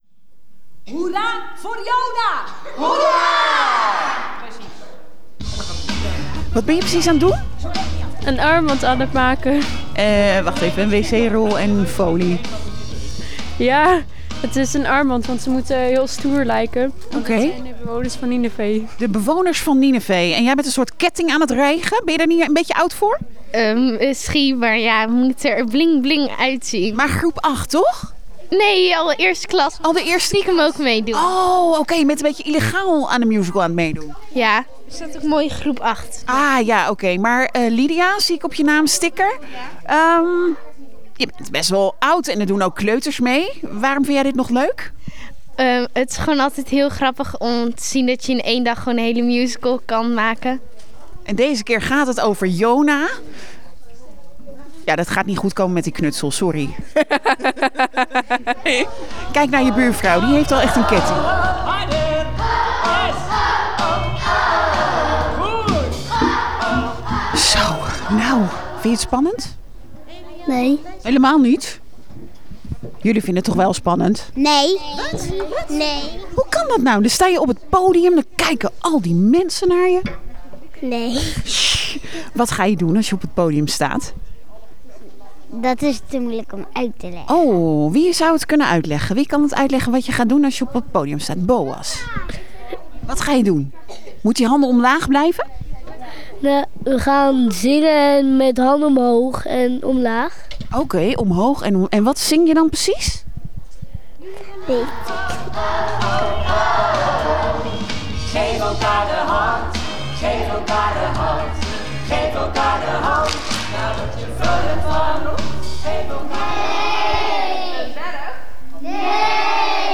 Vanochtend vroeg waren we even te horen op Groot Nieuws Radio. Met een mini reportage van een speeldoosdag. Gisteren waren we te gast in Ede, in Siloam.